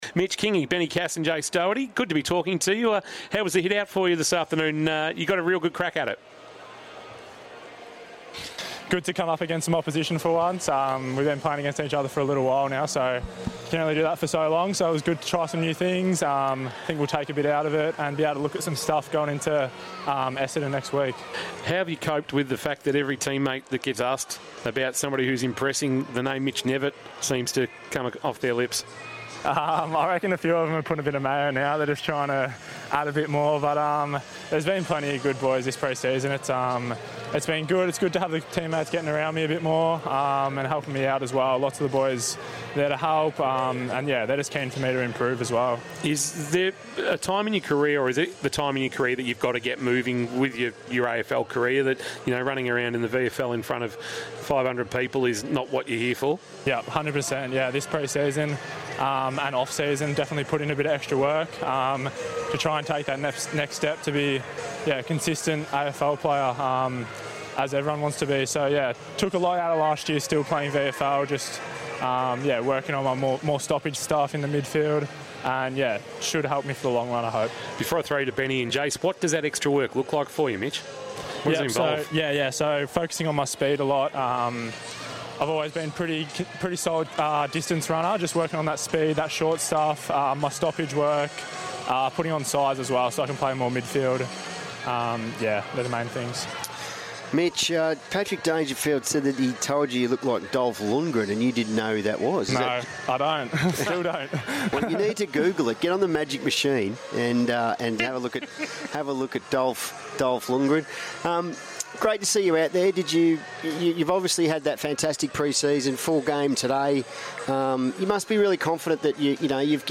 2025 - AFL - Match Simulation - Geelong vs. Hawthorn: Post-match interview - Mitch Knevitt (Geelong)